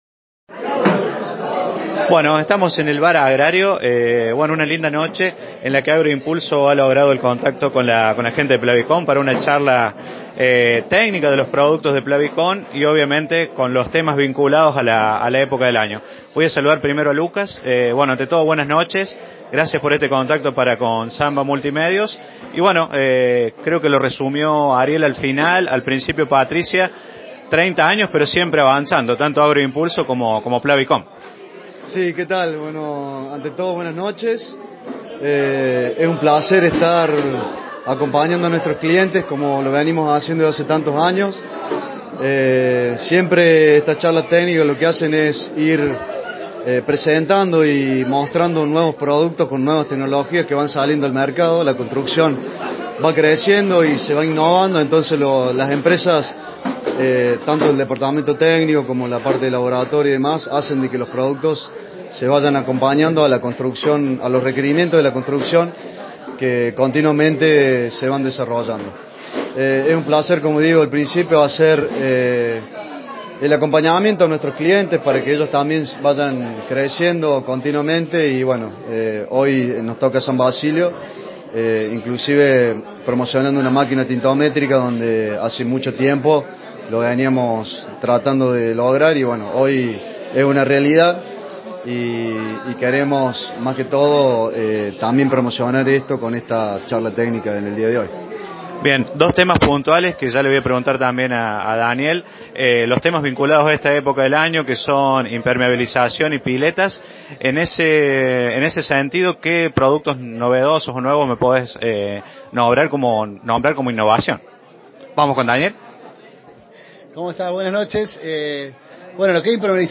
El miércoles 28 de Septiembre se desarrolló en San Basilio una charla Técnica sobre productos Plavicon y presentación de un anhelo de Agroimpulso: La Máquina Tintométrica.
Escuchamos a continuación la palabra de los disertantes: